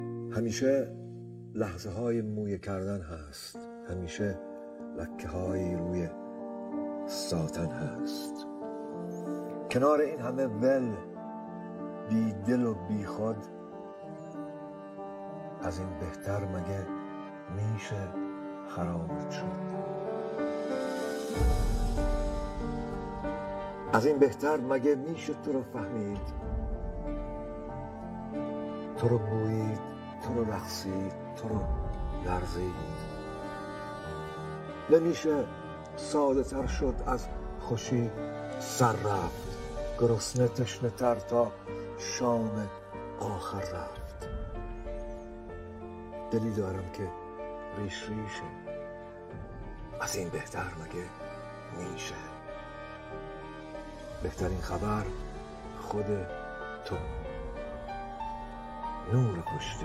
دانلود دکلمه نورپشت در با صدای شهیار قنبری
گوینده :   [شهیار قنبری]